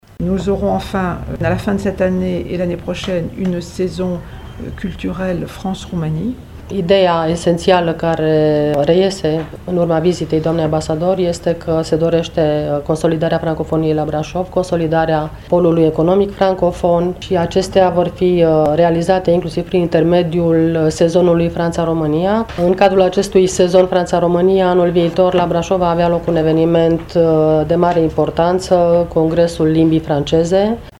La conferința de presă, ambasadorul a anunțat că se dorește consolidarea francofoniei și a mediului de afaceri francofon la Brașov, ceea ce se poate realiza și prin intermediul unei manifestări de excepție, care va începe la finalul anului și care a fost anunțată în premieră: